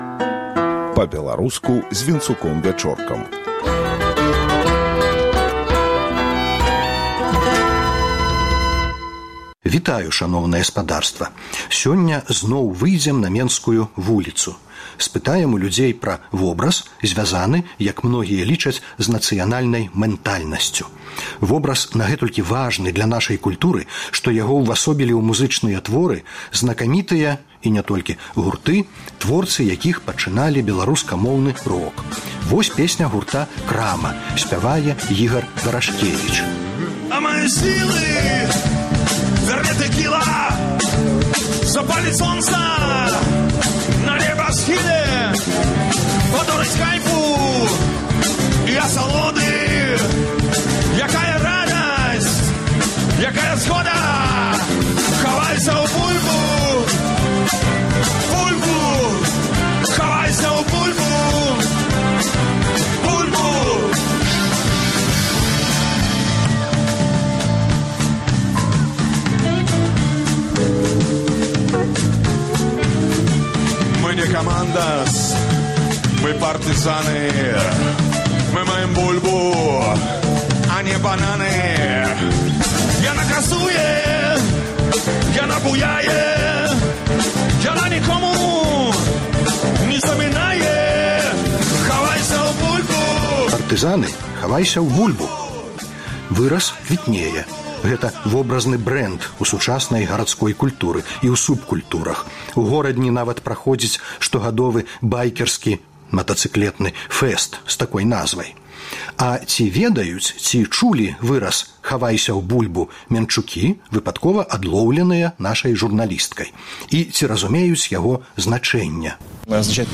А куды хаваюцца ўкраінцы? Апытаньне на вуліцы.